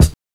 HATS.wav